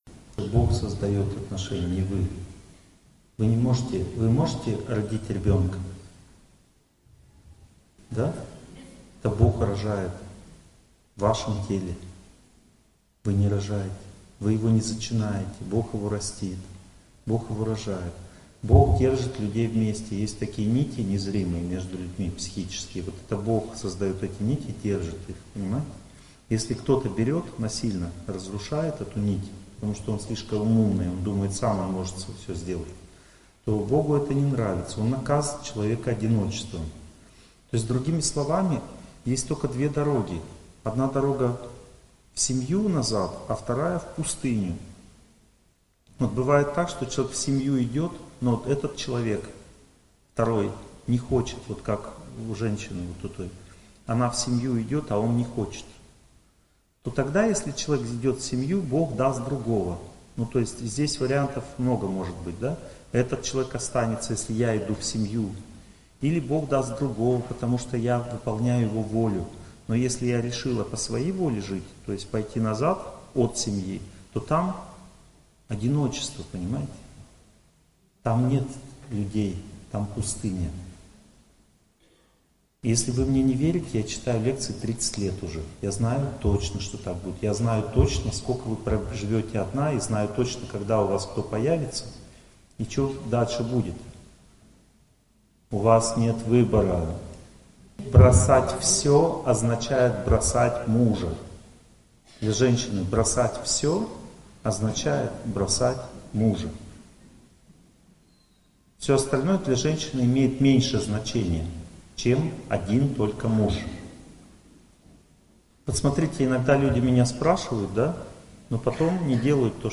Аудиокнига Чувство собственного достоинства, судьба, успех. Часть 1 | Библиотека аудиокниг